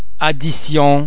Please be mindful of the fact that all the French sounds are produced with greater facial, throat and other phonatory muscle tension than any English sound.
The French [d] and [dd] are normally pronounced a single [d] sound as in the English words addres, addition, dad, drive etc.